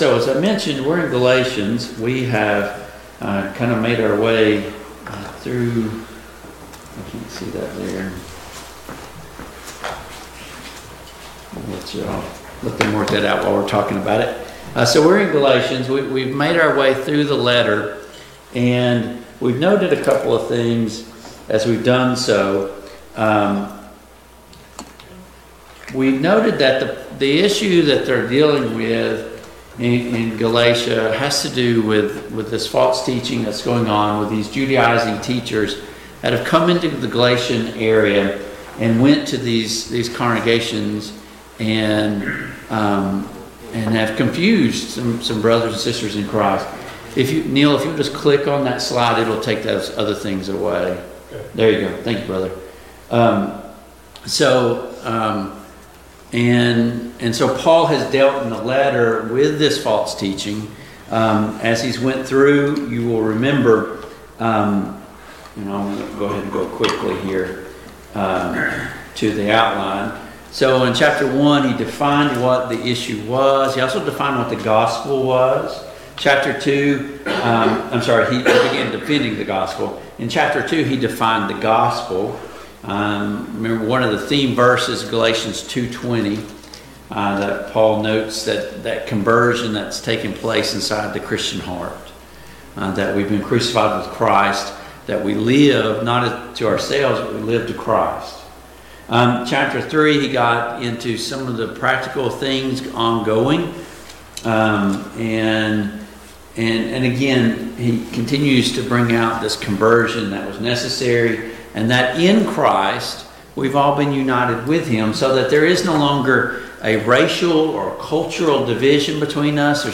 Passage: Galatians 6, Ephesians 1:1-3 Service Type: Mid-Week Bible Study